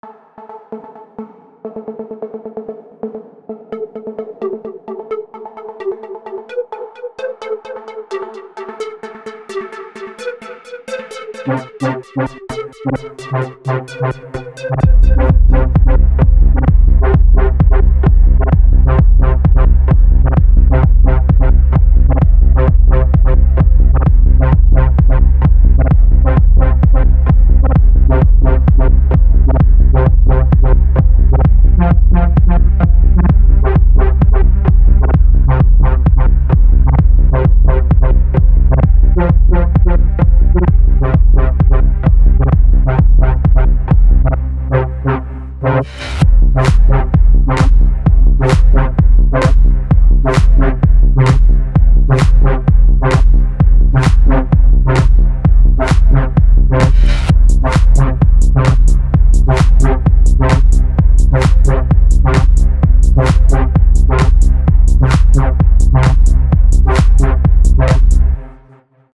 Скачать Минус
Стиль: House